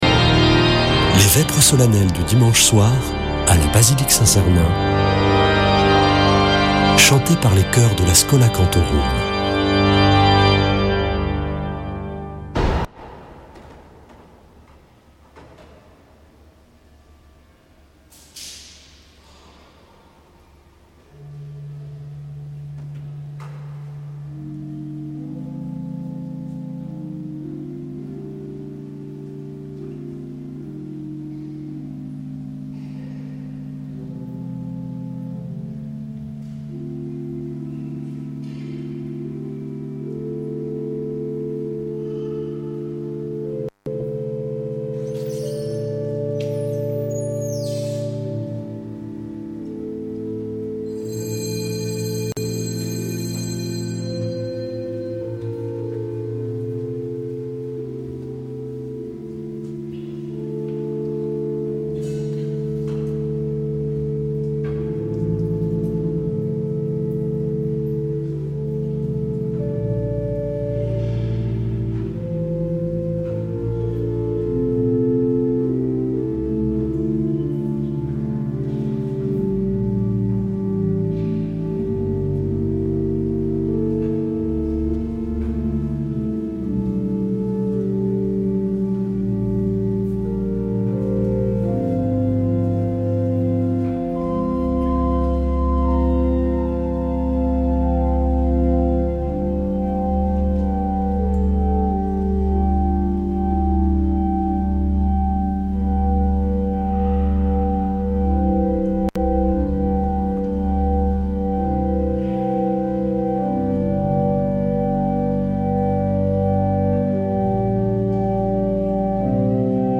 Accueil \ Emissions \ Foi \ Prière et Célébration \ Vêpres de Saint Sernin \ Vêpres de Saint Sernin du 19 avr.
Une émission présentée par Schola Saint Sernin Chanteurs